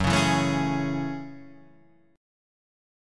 Gbm9 chord